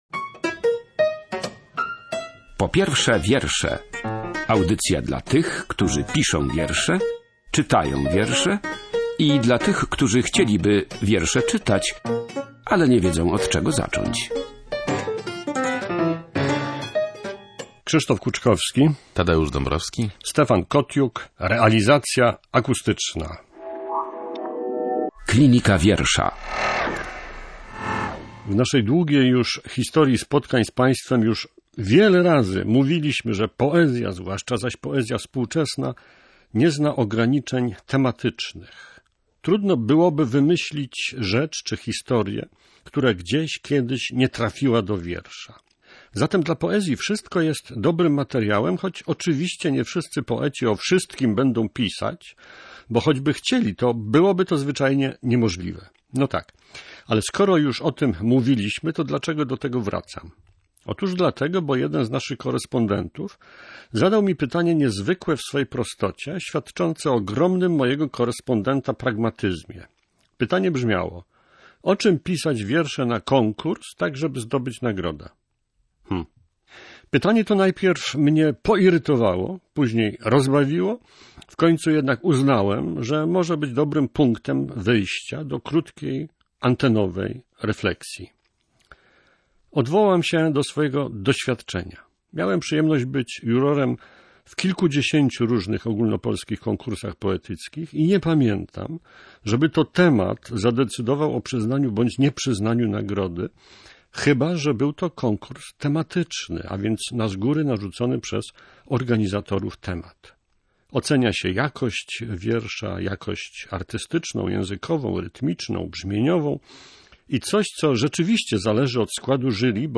Czy temat wiersza jest istotny i jak bardzo wpływa na ocenę i odbiór twórczości - o tym rozmawiali prowadzący audycję